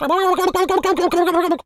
pgs/Assets/Audio/Animal_Impersonations/turkey_ostrich_gobble_01.wav at master
turkey_ostrich_gobble_01.wav